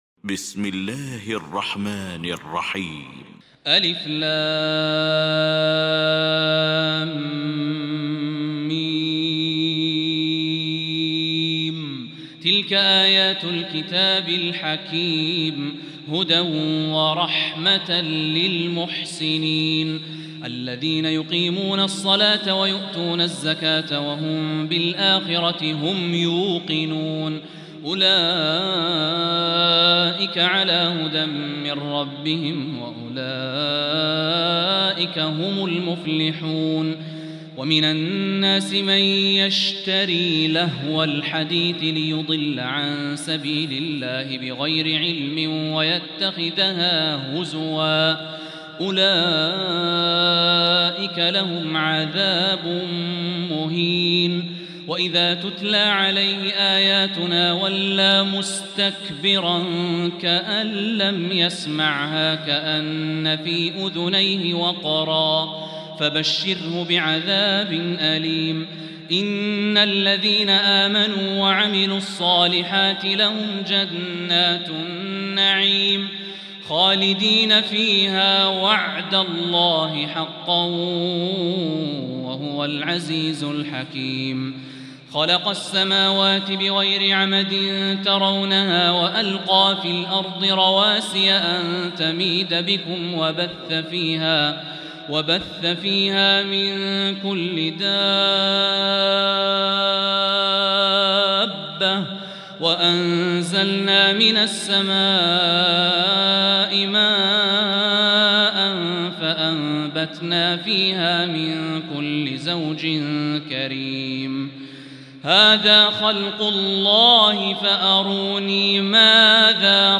سورة لقمان | تراويح الحرم المكي عام 1445هـ